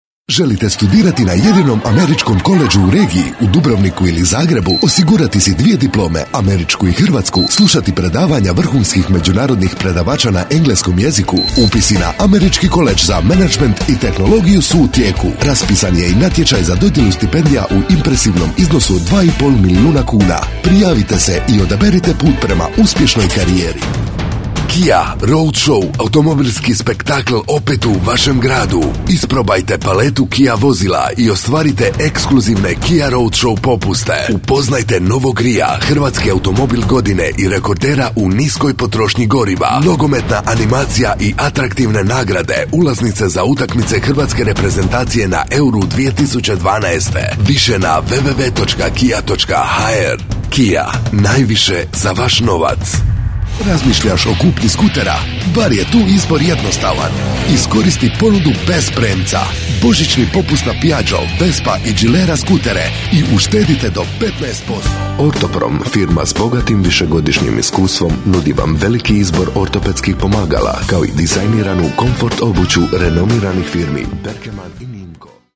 Im croatian native speaker warm-intimate voice and Croatian literary pronunciation
Sprechprobe: Werbung (Muttersprache):